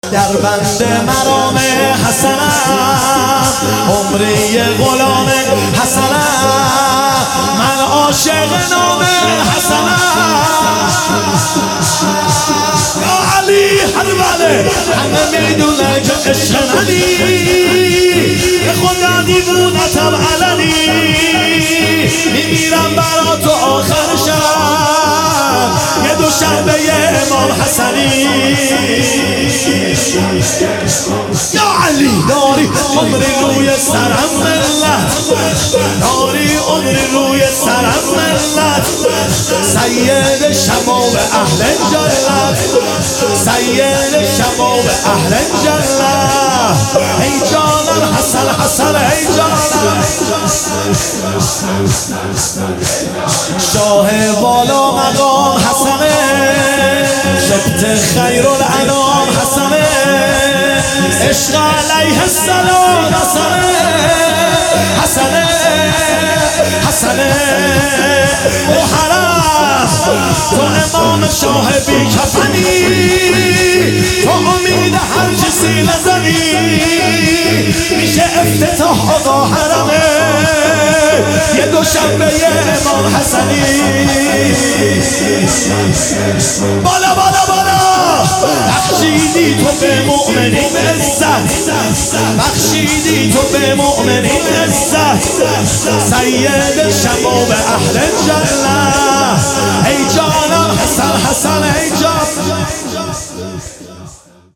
هیئت عشاق الرضا (ع) تهران |محرم 1399